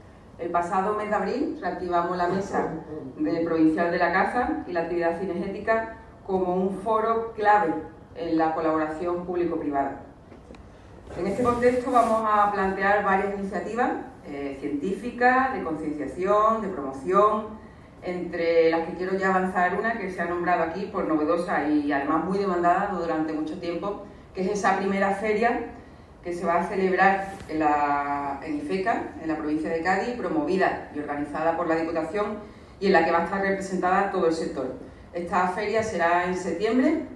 La presidenta valora la aportación económica y medioambiental de la industria cinegética en unas jornadas organizadas en Jerez
Feria-actividad-cinegetica_Almudena-Martinez.mp3